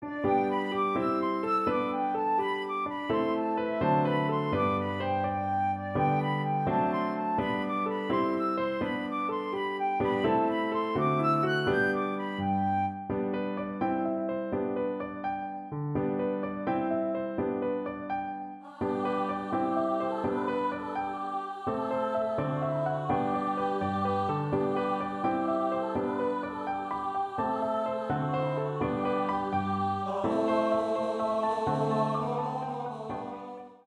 An arrangement for SATB, flute and piano